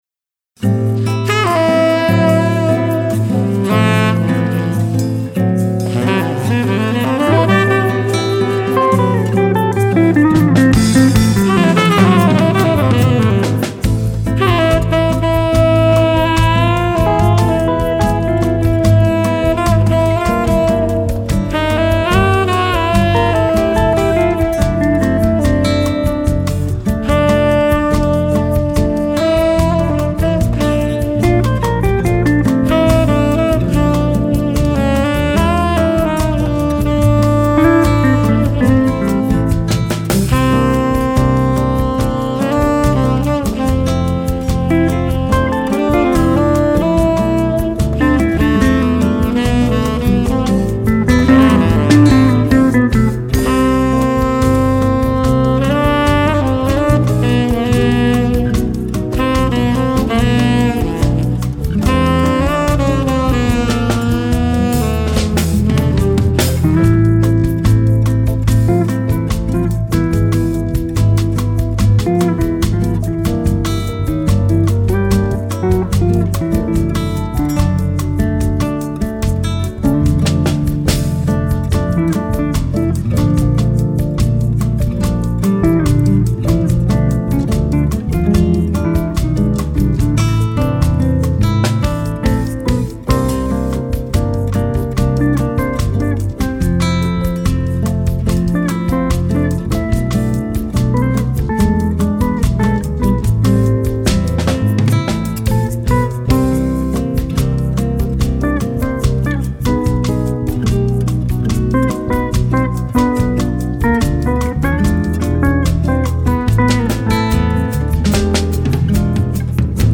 760   03:30:00   Faixa:     Bossa nova
Guitarra